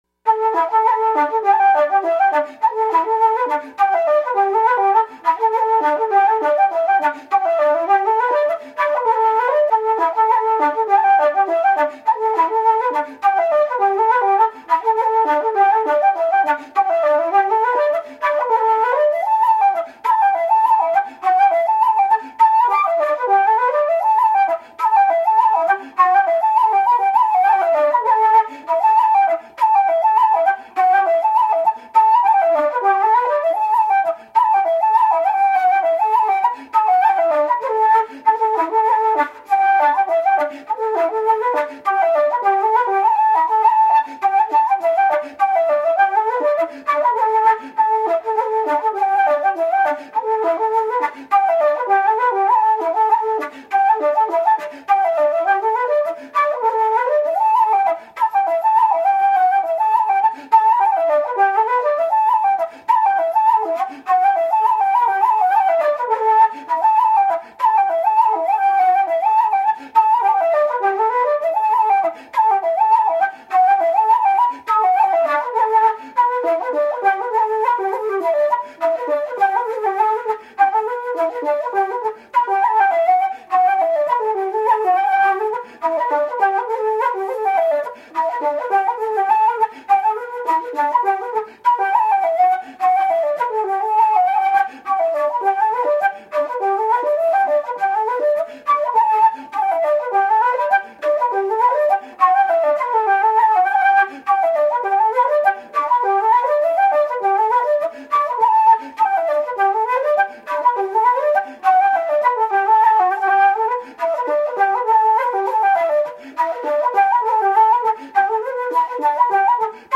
Irish Traditional Music
who plays flute, tin whistle and uilleann pipes.
fiddle
fiddle and life-long friend on bodhran